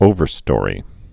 (ōvər-stôrē)